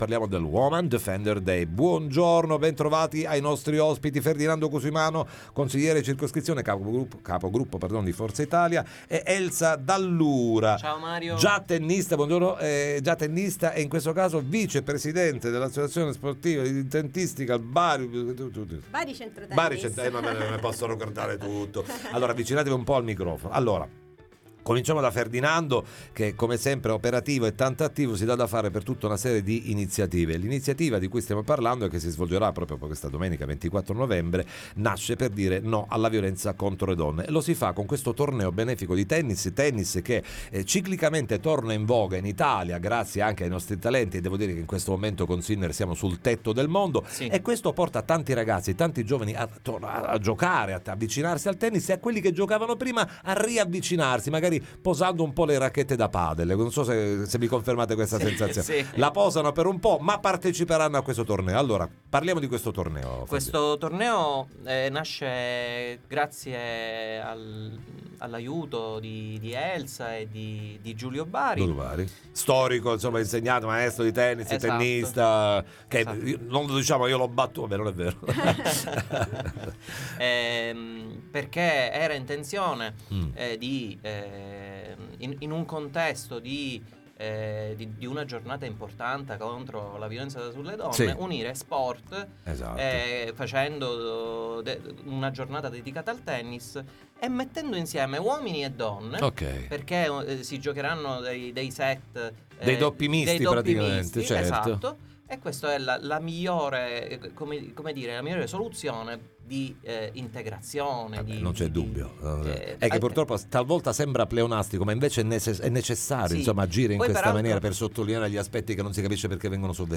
Woman Defender Day 2024, ne parliamo con gli organizzatori ai ns. microfoni